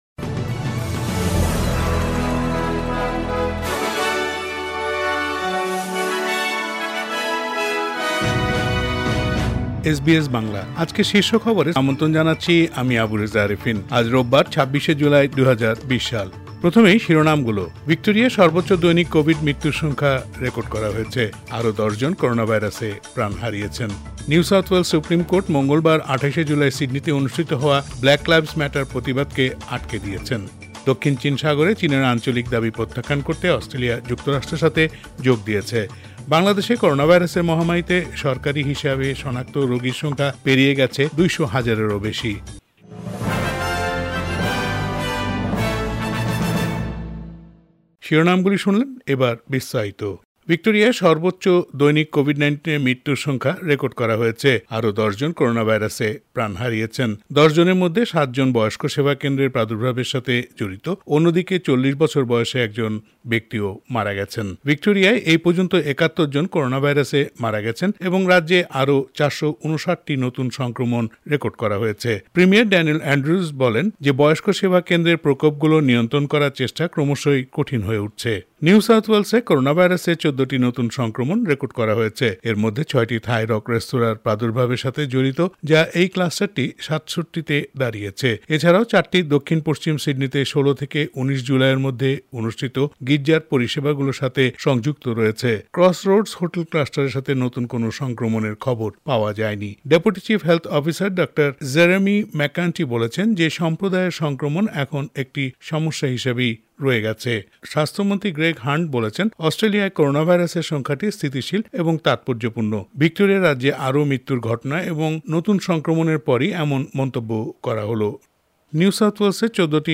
sbs_bangla_news_26_july_final.mp3